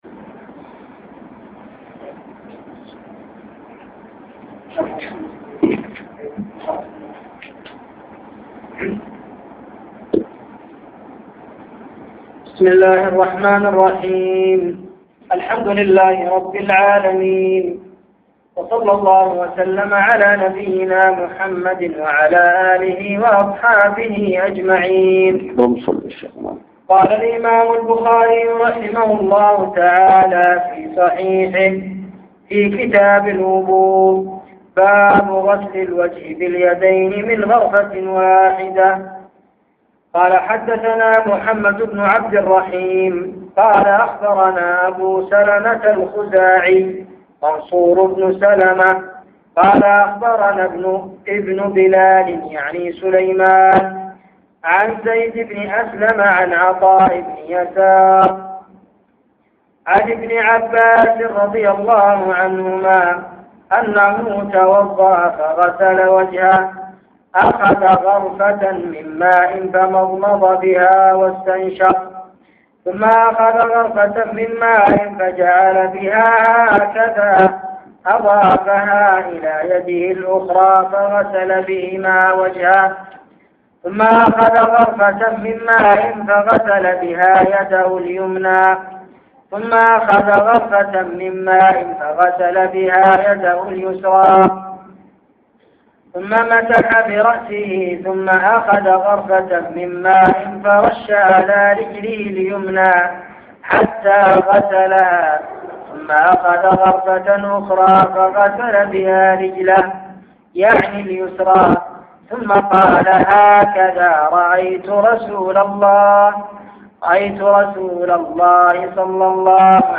سلسلة دروس شرح صحيح البخاري - شرح مسجد الفتاح بضمد